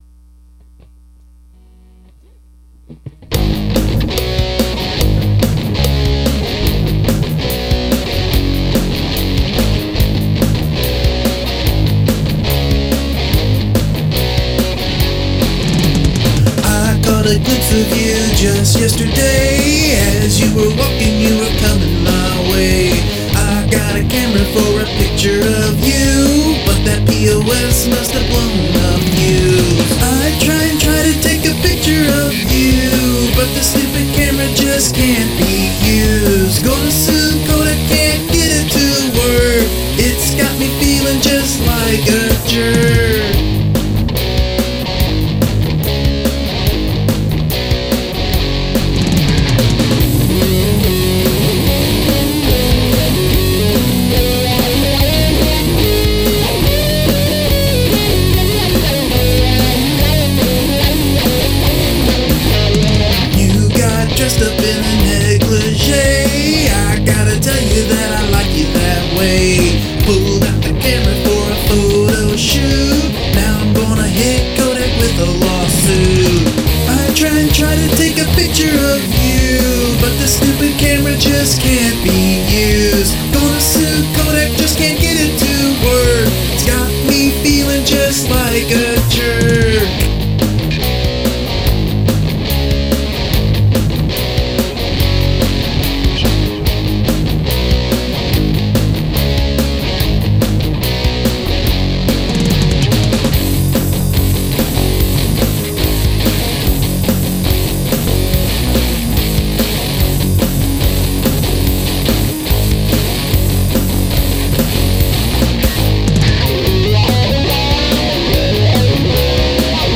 Got a new (to me) wah pedal and decided to use it in a song.
Great driving chords really do power this song along nicely, gives it a Punk vibe.
You have a great punky grind for the foundation, and some lovely wah running wild over the top.
Great rocker, nice story-lyrics.....